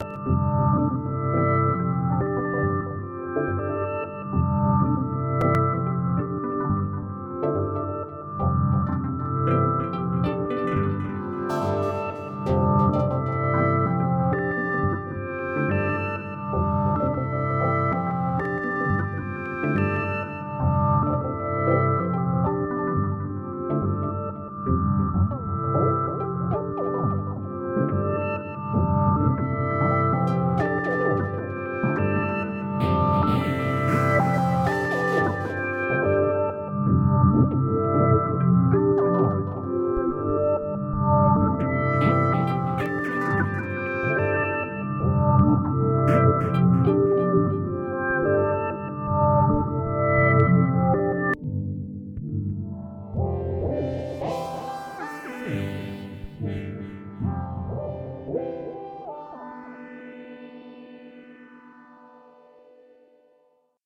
Misty and dreamlike…Servant of the Mind and Studio.
MICROKORG7.mp3